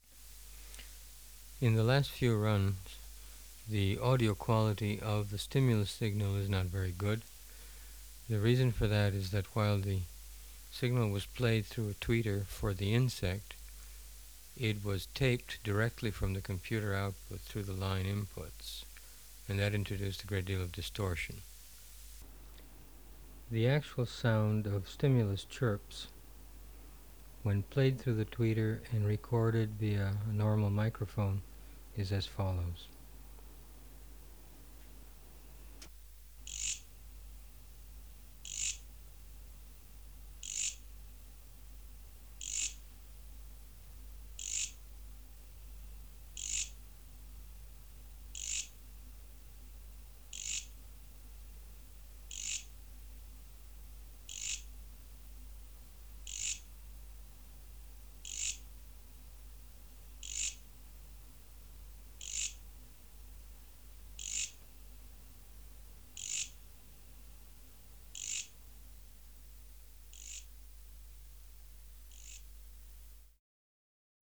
Project: Natural History Museum Sound Archive Species: Mecopoda "S"
Sample of computer - generated stimuli recorded without distortion.